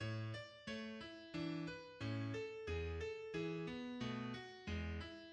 The quiet opening returns after this and leads into the A major second theme group, called "Schubertian" by Georg Tintner:[5]